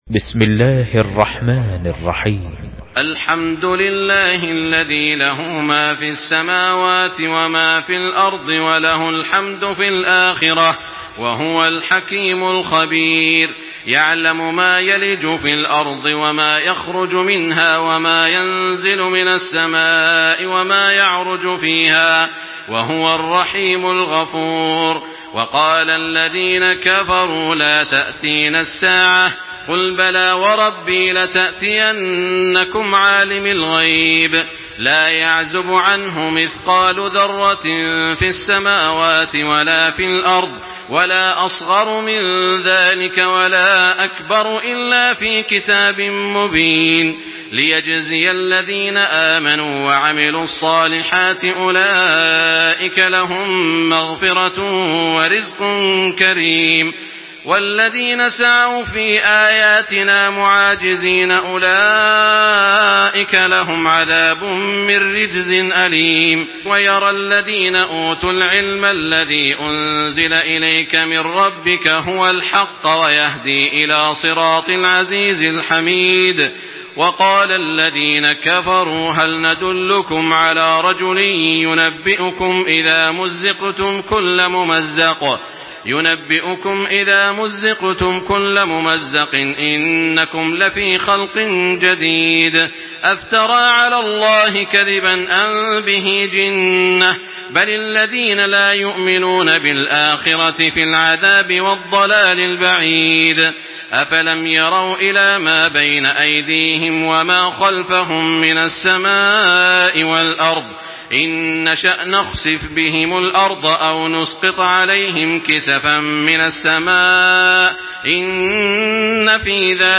تحميل سورة سبأ بصوت تراويح الحرم المكي 1425
مرتل حفص عن عاصم